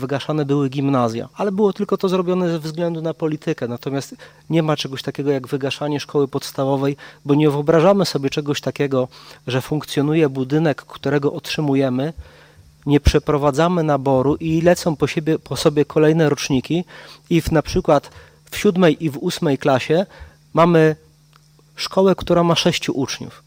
Mieszkańcy Kobylan chcieliby wygaszenia, czyli kolejne roczniki szkoły odchodziłyby, a nie przeprowadzano by naboru do klasy pierwszej. Mówi wiceburmistrz Marcin Słapek.